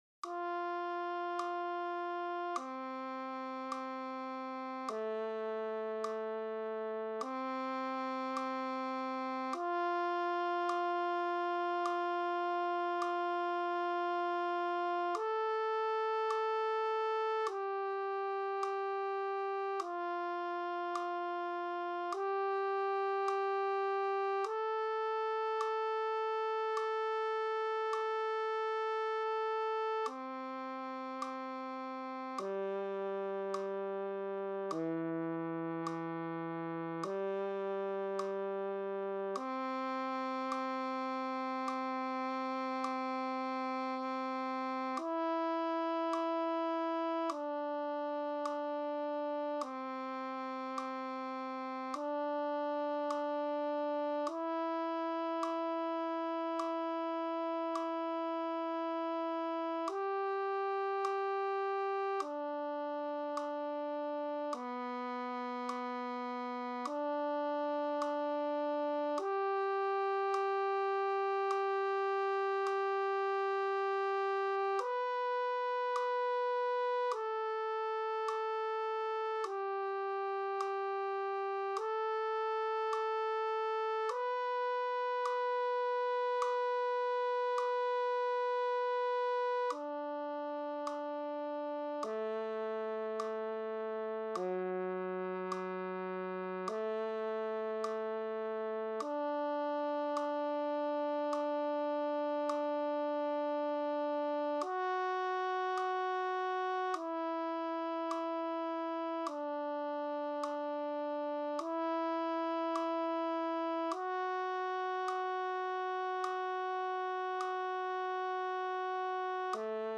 To create this exercise I used Sibelius notation software, and then exported the file as audio (see below).
The well-known pattern, “horn fifths”, goes through every key via the circle of fifths. The audio file will provide a pitch reference to gauge intonation and make adjustments.
One caveat: the audio track does not use tempered intonation, so the player will have to make all of the requisite adjustments (low major thirds, etc.).